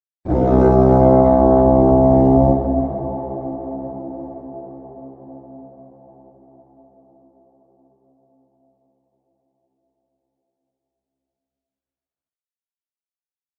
trumpet.mp3